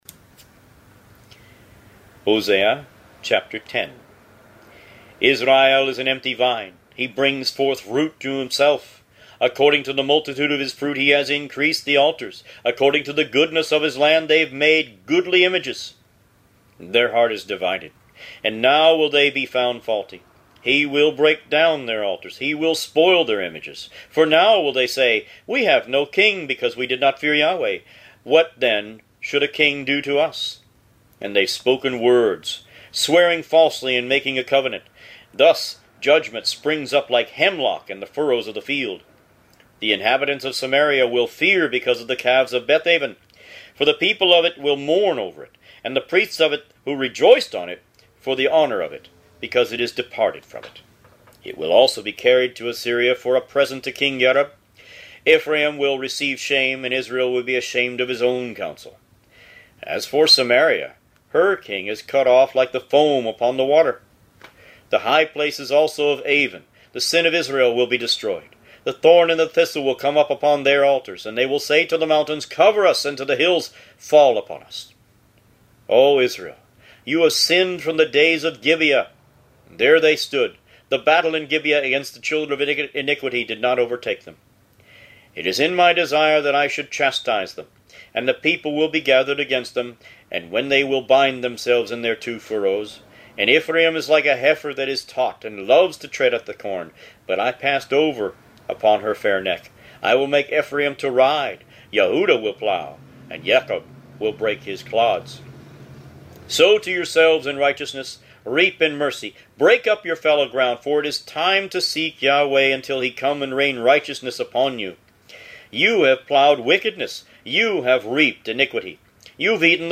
Root > BOOKS > Biblical (Books) > Audio Bibles > Tanakh - Jewish Bible - Audiobook > 28 Hosea